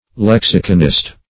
Lexiconist \Lex"i*con*ist\, n.